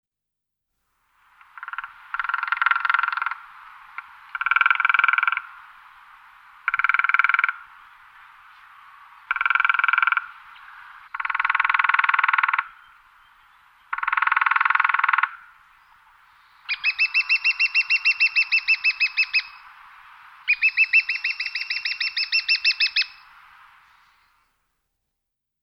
На этой странице собраны звуки дятла: от ритмичного стука по дереву до редких голосовых сигналов.
Звук дятла в природе